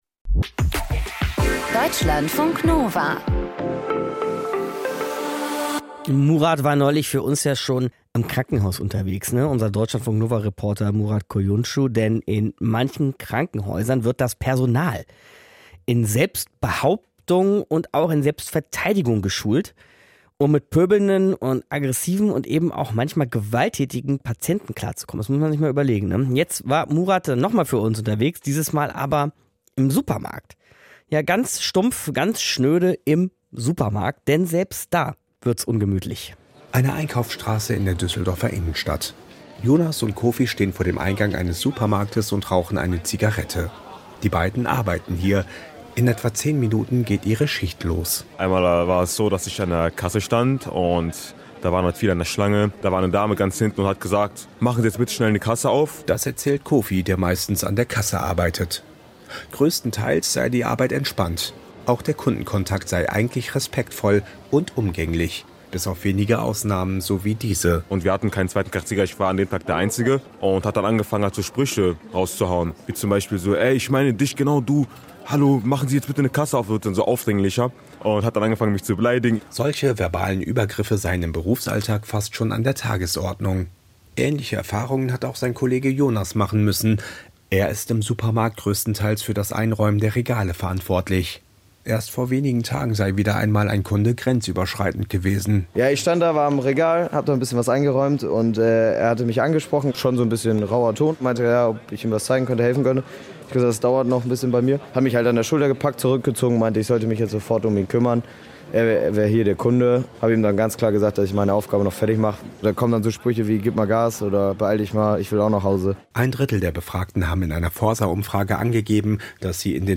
Streitgespräch - Kontrovers
Viele Hörerinnen und Hörer, drei Gesprächsgäste, ein/e Moderator/in: In „Kontrovers“ prallen viele Meinungen zu einem Thema aufeinander.